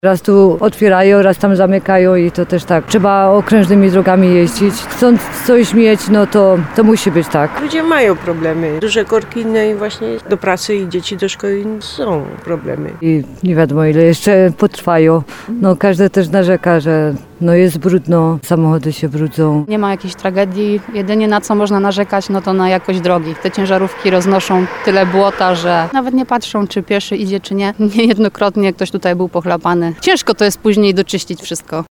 5sonda_mordarka_utrudnienia_kolejowe.mp3